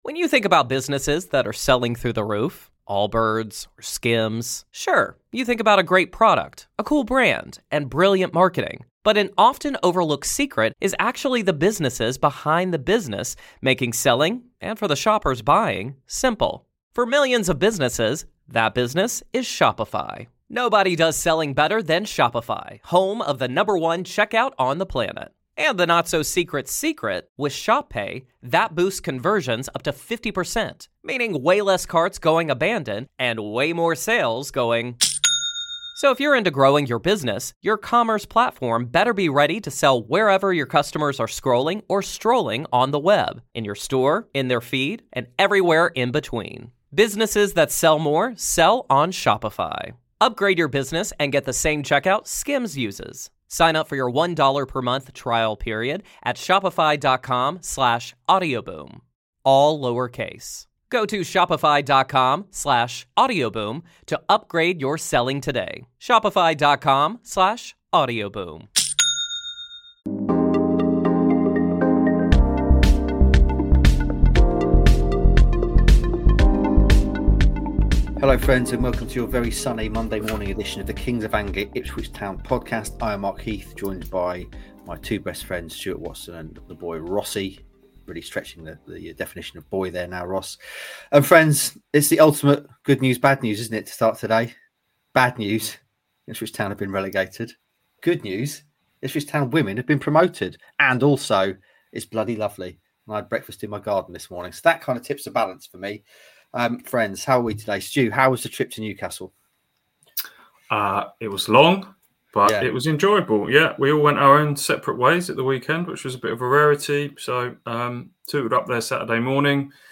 tries his hand at a Geordie accent - and aces it!